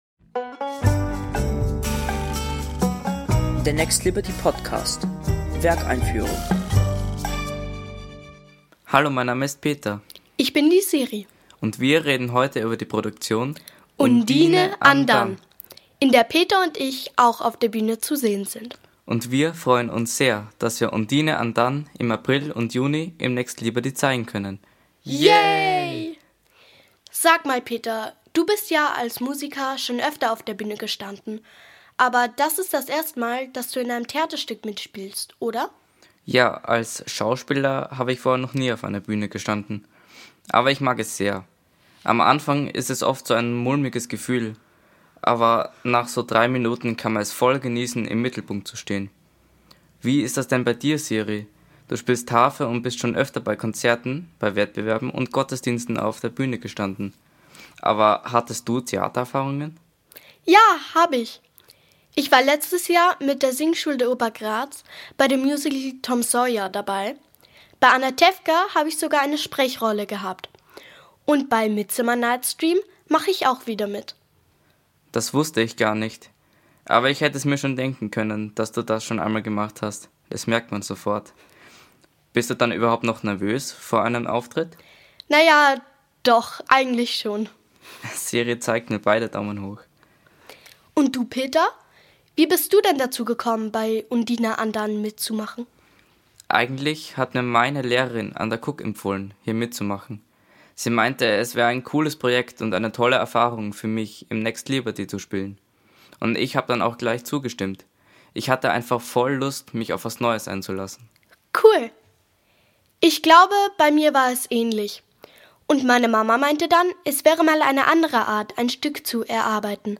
Das und mehr erfahrt ihr in unserer Audio-Einführung mit O-Tönen der Darsteller:innen, Statements vom Team und natürlich musikalischen Eindrücken.